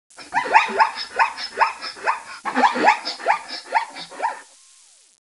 zebra6.wav